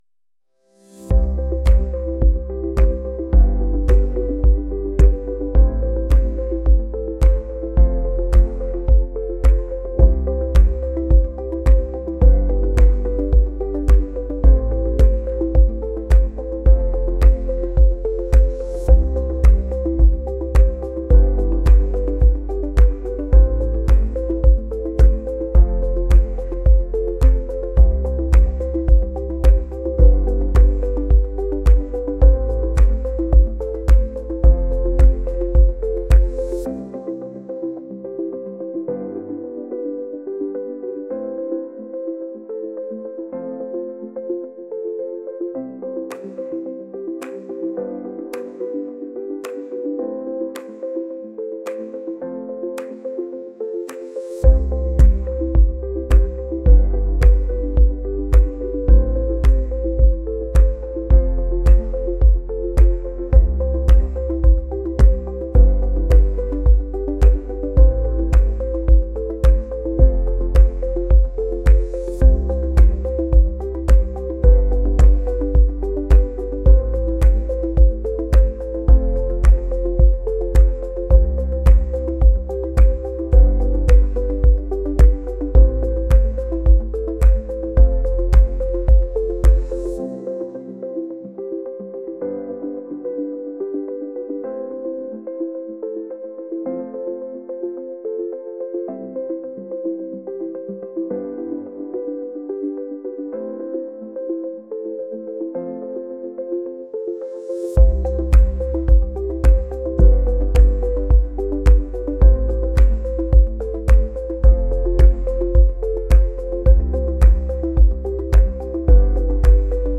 electronic | upbeat | pop